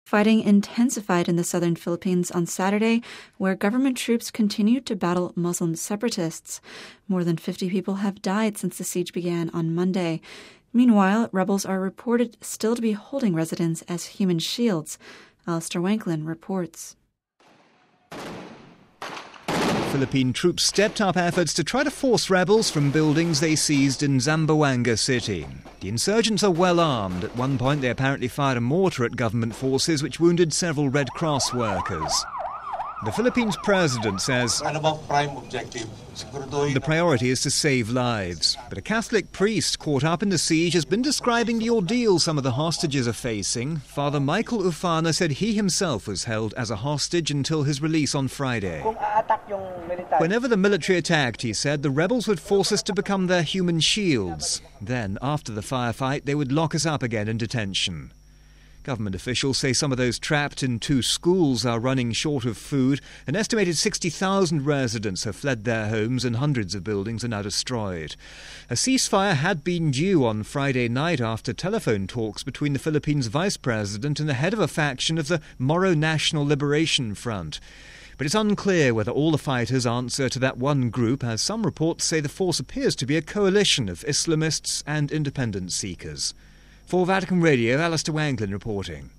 full report